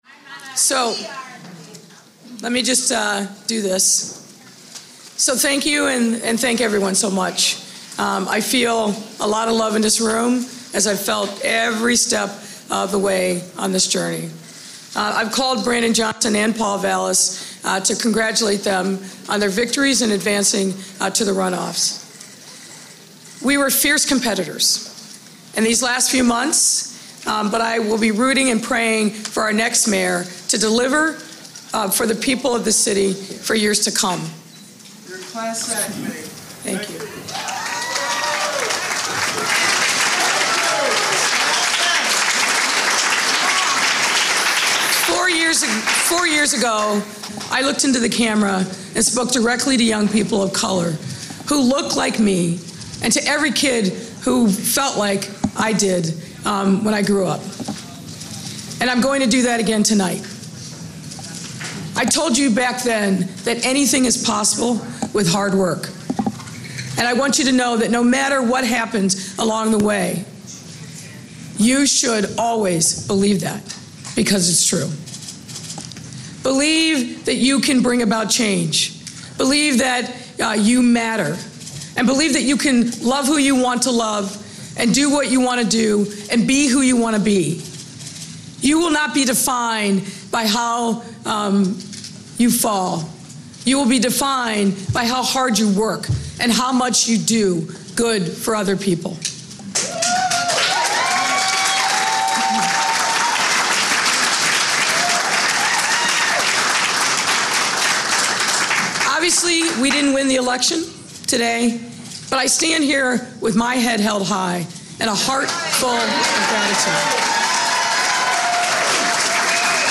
Chicago Mayoral Concession Speech
Audio Note: Audio clip created from two discrete sources to render a complete set of delivered remarks. AR-XE = American Rhetoric Extreme Enhancement